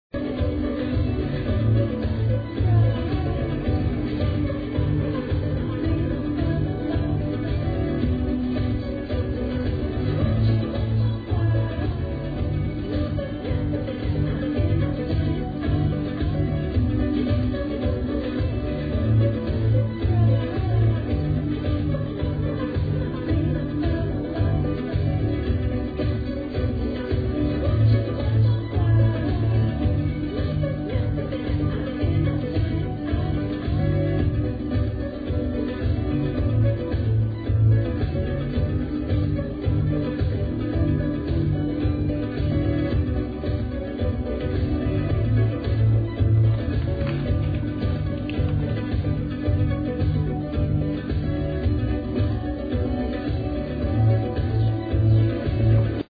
another bad recording 2 a nice vocal tune